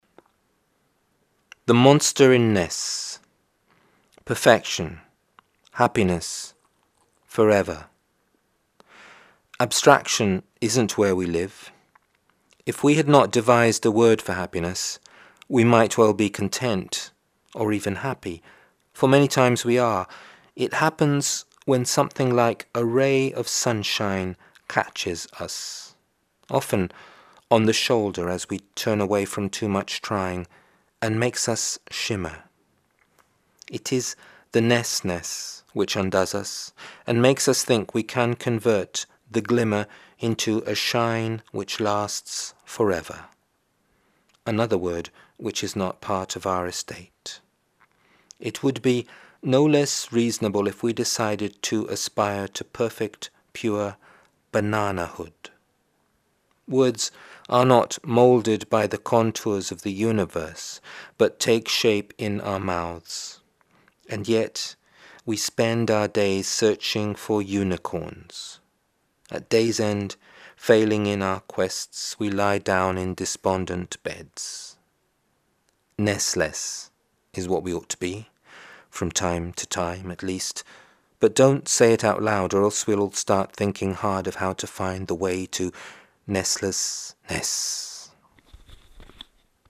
Such a pleasure, listening to you, reading your poems.